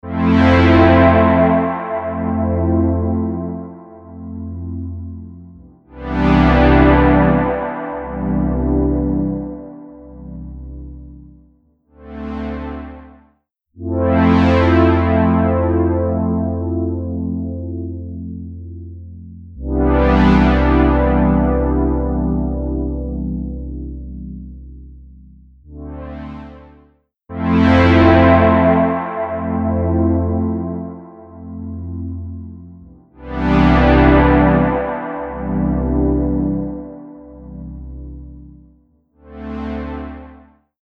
Tube Amp-style Spring Reverb
Spring | Synth | Preset: Expand … Contract
Spring-Eventide-Synth-Pad-Expand-...-Contract.mp3